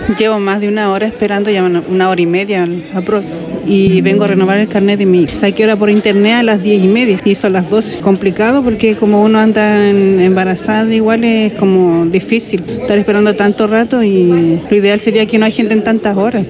En concreto, en la sucursal del centro de Osorno -a la cual se debe asistir exclusivamente con reserva de hora para el tramite en cuestión- se supera la hora de espera, así lo lamentó una usuaria.